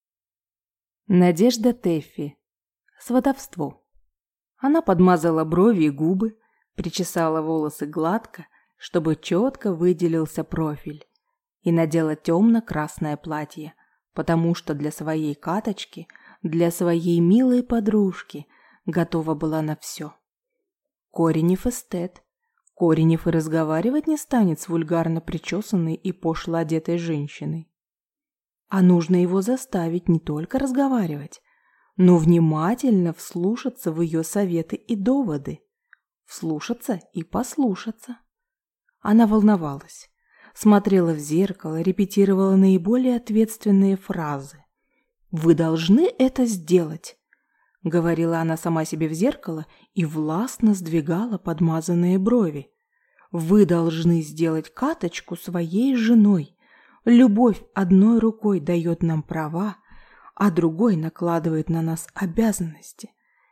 Аудиокнига Сватовство | Библиотека аудиокниг
Прослушать и бесплатно скачать фрагмент аудиокниги